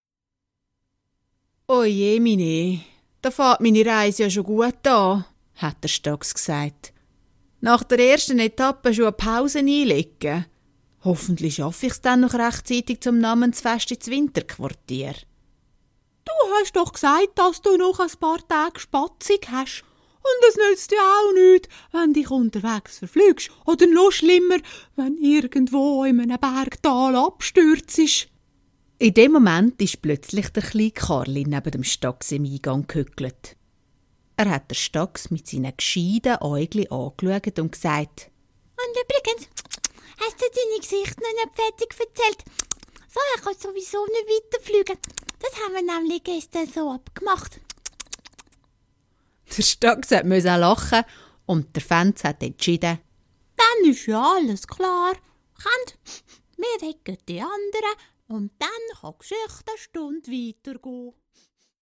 CD Dialekt Hörspiel Staks
Dialekthörspiel mp3 Staks